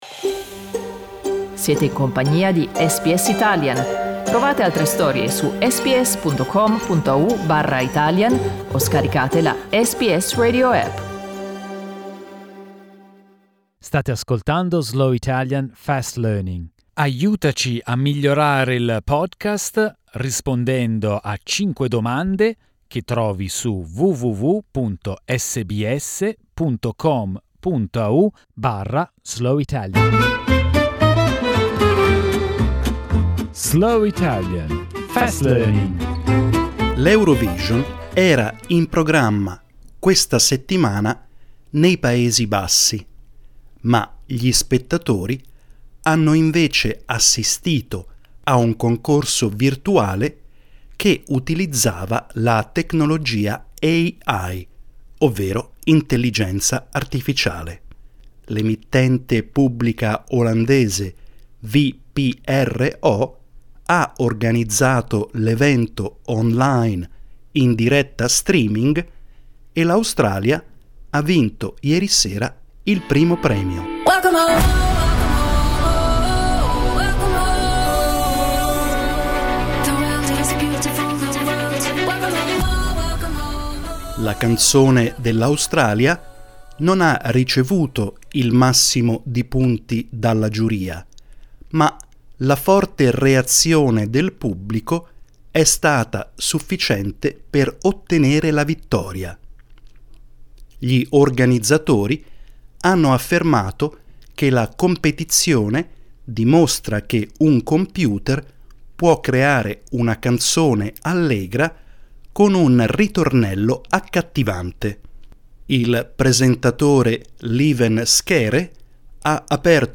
Slow Italian, Fast Learning